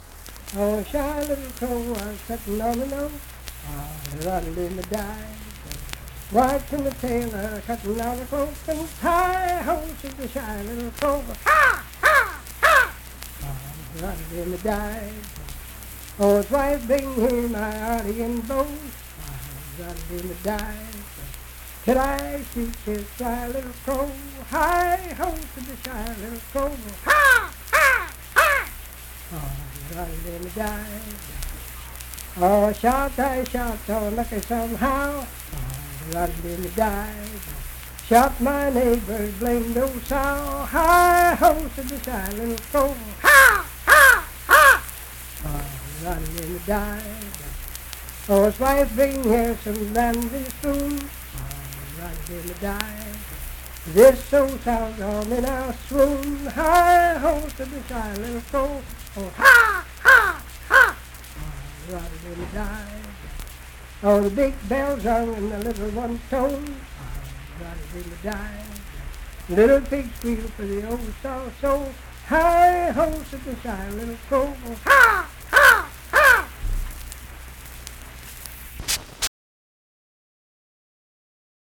Unaccompanied vocal music
Verse-refrain 5(5w/R). Performed in Ivydale, Clay County, WV.
Voice (sung)